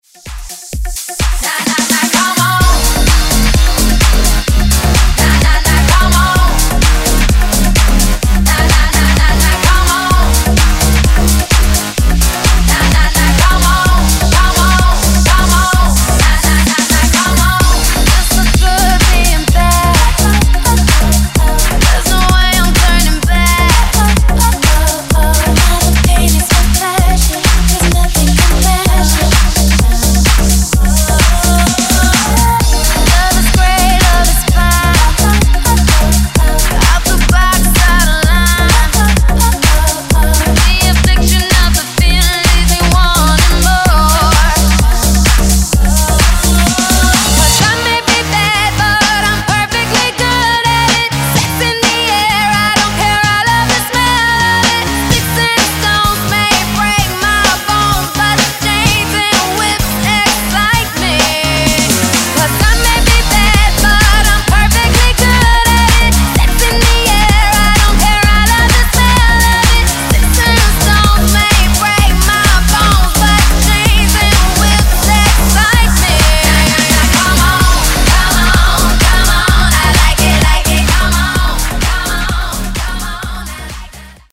Genre: DANCE
Clean & Dirty BPM: 128 Time